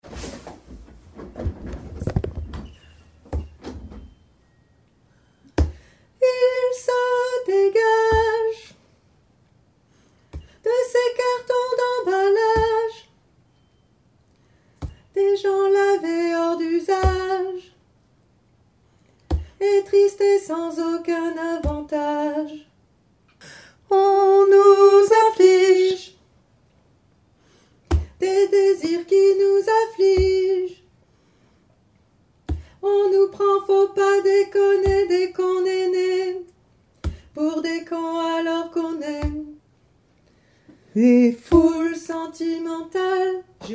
Couplet 2 alti 1